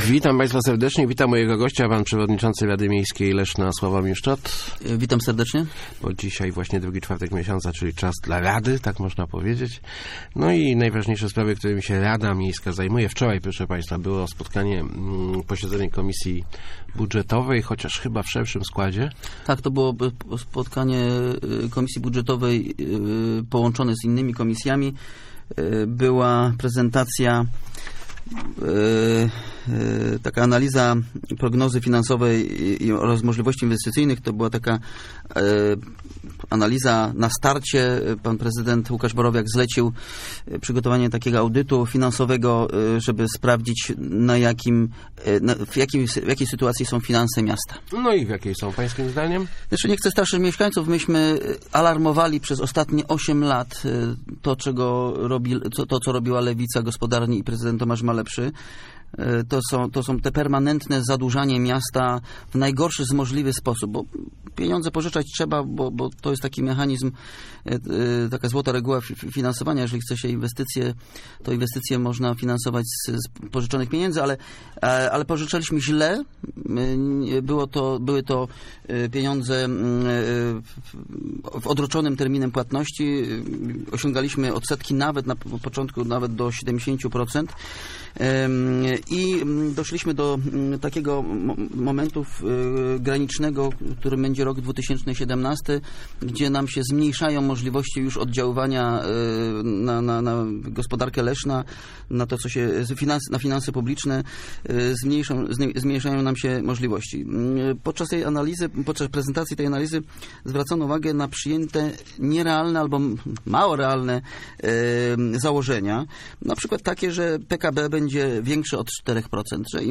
Gościem Kwadransa byli przewodniczący Rady Miejskiej Leszna Sławomir Szczot i radny Tomasz Malepszy.